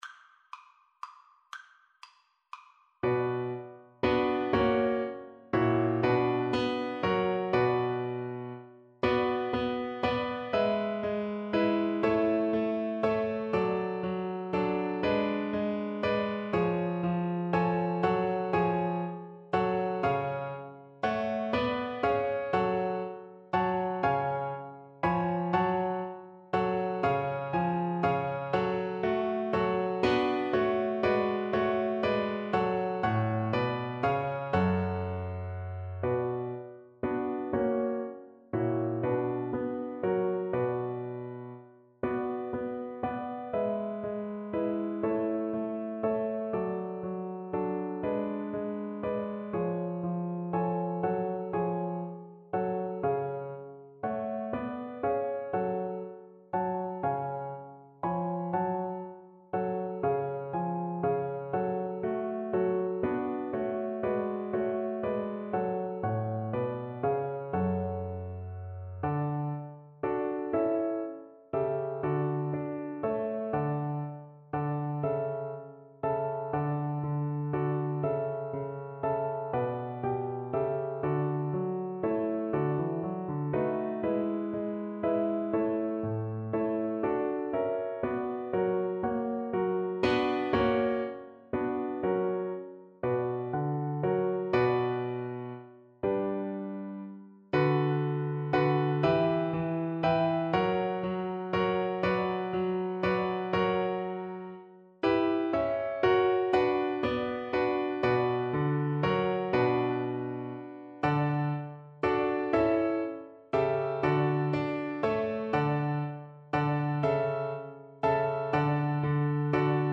Violin
3/8 (View more 3/8 Music)
D5-B6
B minor (Sounding Pitch) (View more B minor Music for Violin )
=120 Vivace (View more music marked Vivace)
Classical (View more Classical Violin Music)